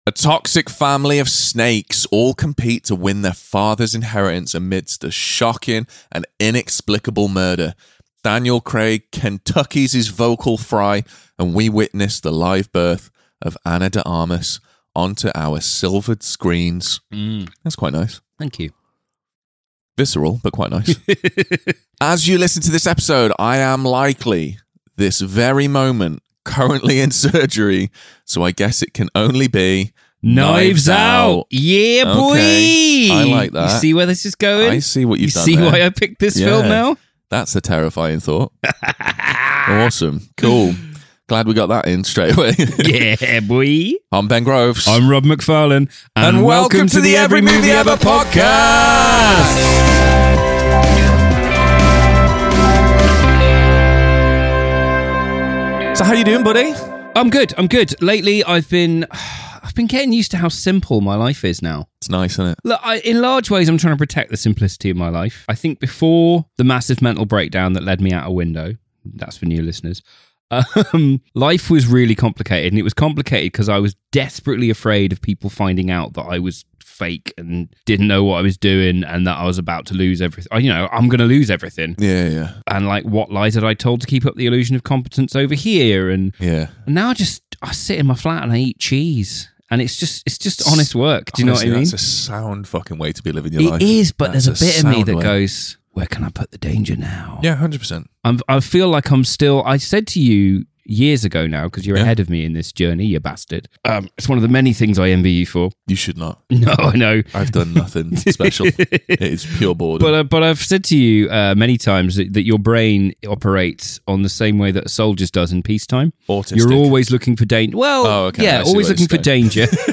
Weekly episodes released every Sunday have the boys talking everything from Hollywood blockbusters to indie darlings and all manner of highs and lows in-between!